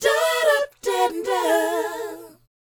DOWOP B BU.wav